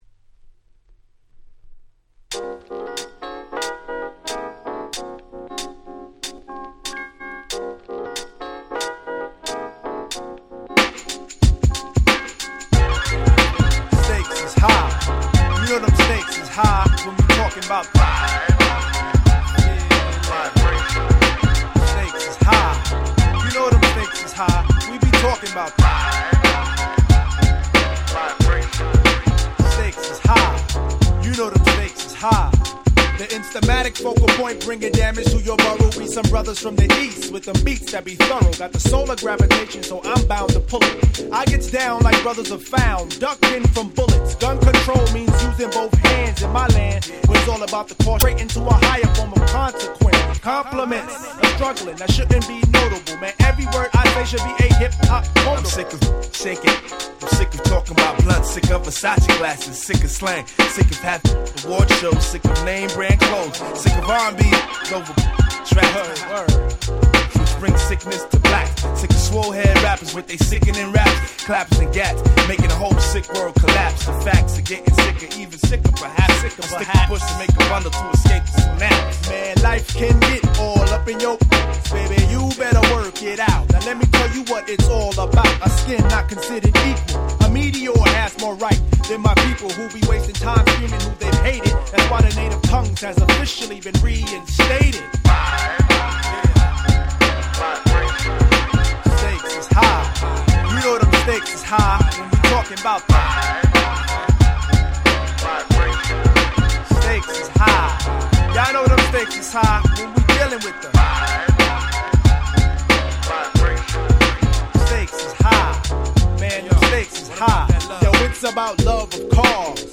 96' Very Nice Hip Hop Mini Album !!